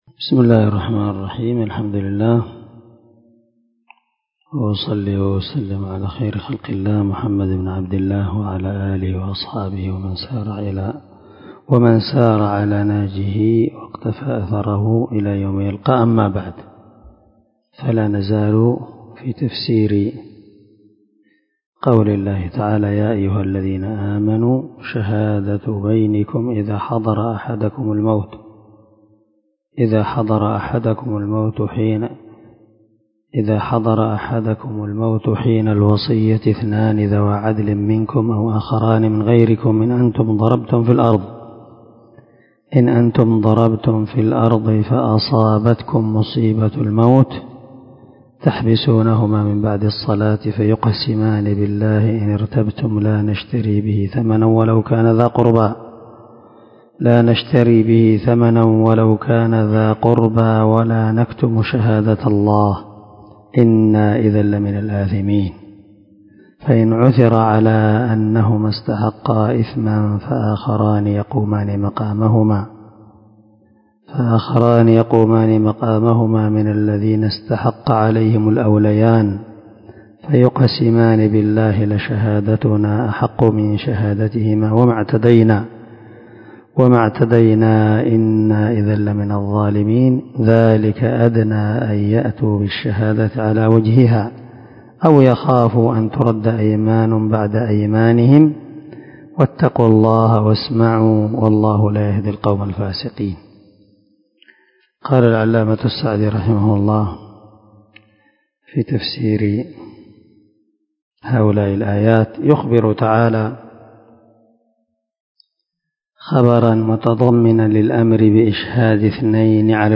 دار الحديث- المَحاوِل